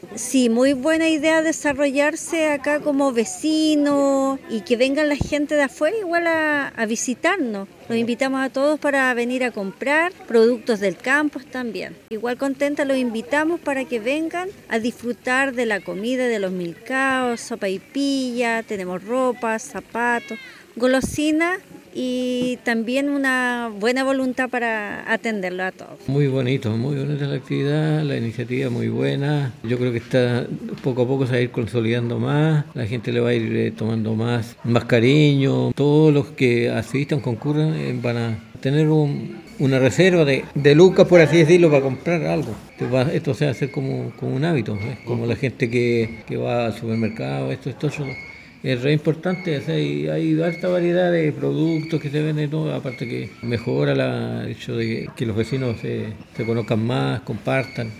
Una tercera versión de la Feria de Barrio Arrebol, se desarrolló el domingo 8 de junio en el sector Aguas Andinas de Purranque.
VECINOS-TERCERA-FERIA.mp3